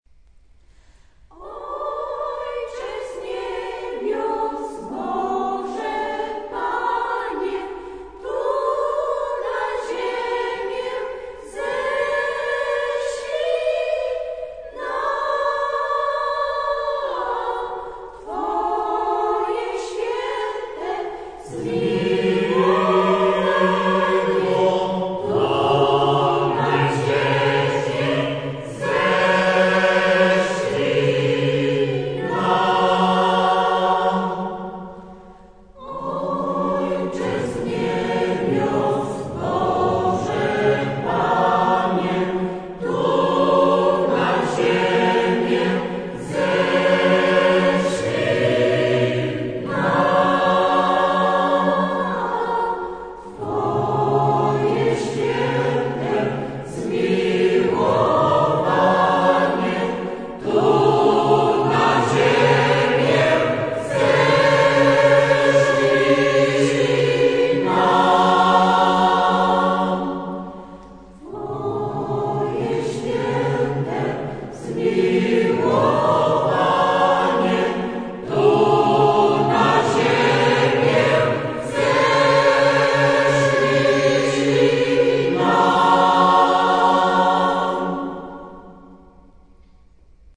Chór Diecezji śląsko - łódzkiej  „MARIAWITA”